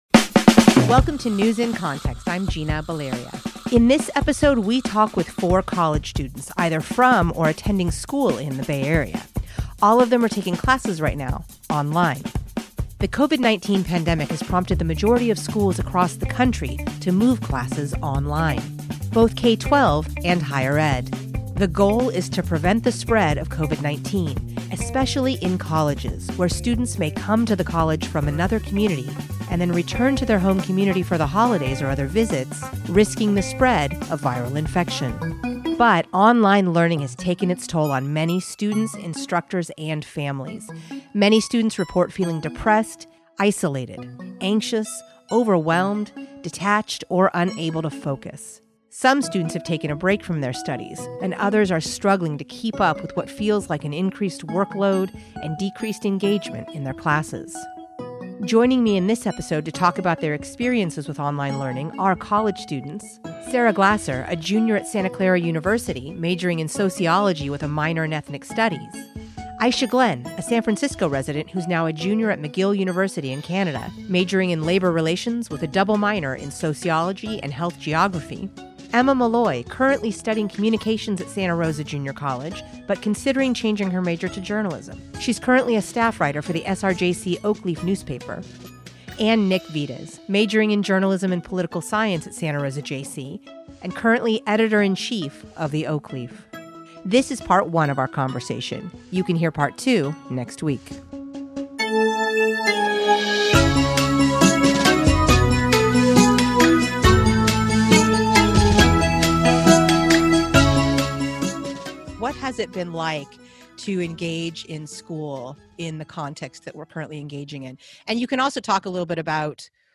In this episode, we talk with four college students from and/or attending school in the Bay Area - all of them taking classes right now online.